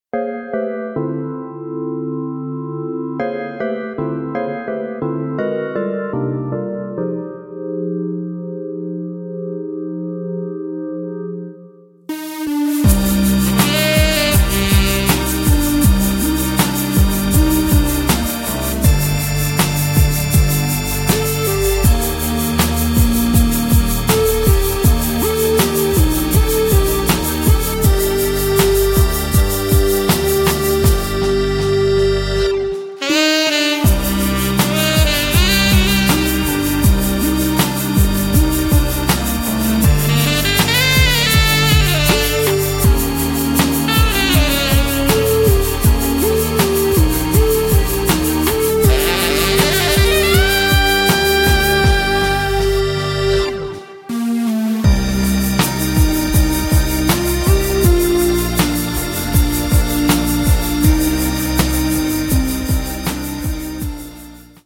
Electro-synth-pop Version